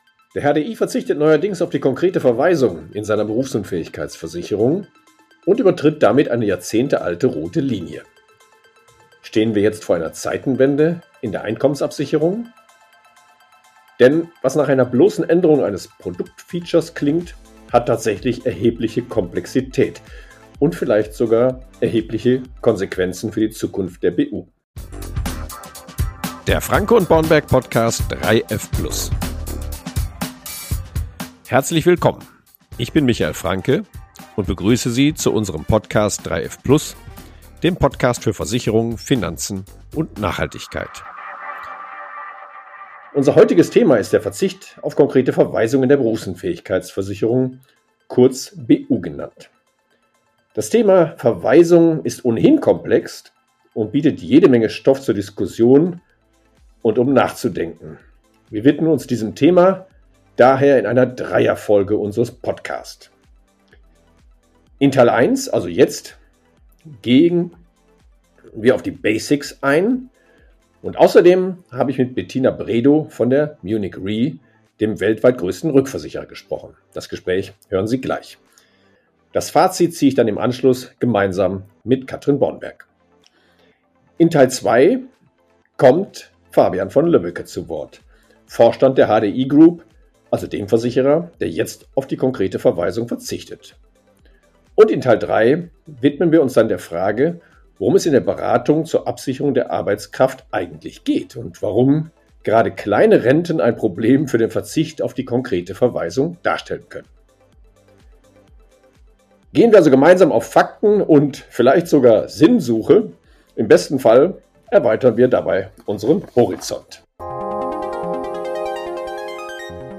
#1 - Konkrete Verweisung (1/3) - Gespräch